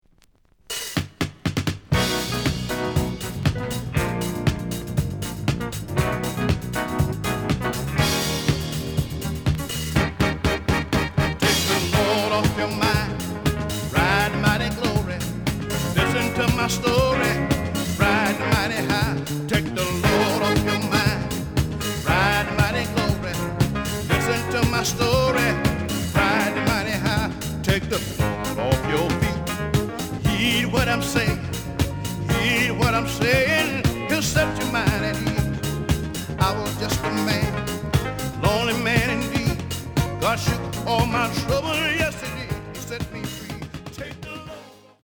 The listen sample is recorded from the actual item.
●Genre: Disco